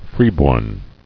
[free·born]